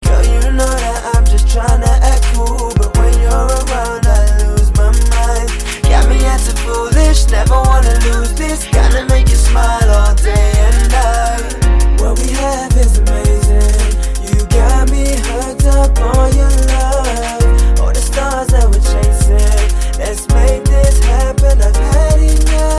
love melody Category